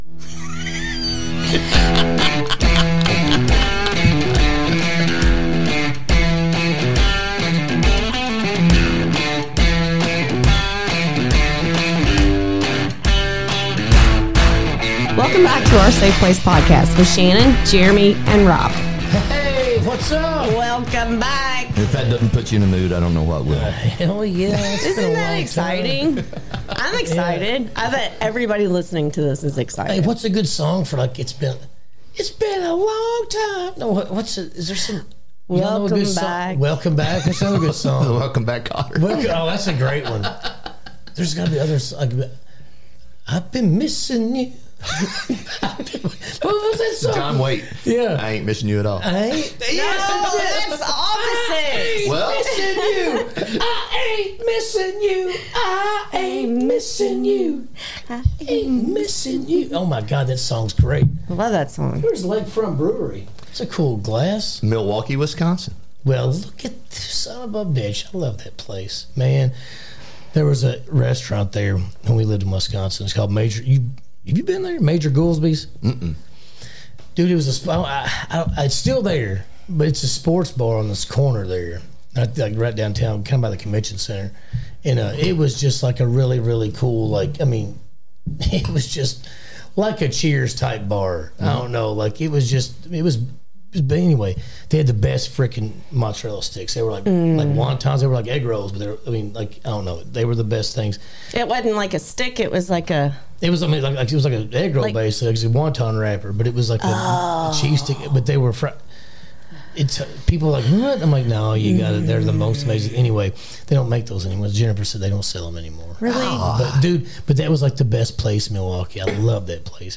Be a guest on this podcast Language: en Genres: Comedy , Comedy Interviews , Personal Journals , Society & Culture Contact email: Get it Feed URL: Get it iTunes ID: Get it Get all podcast data Listen Now...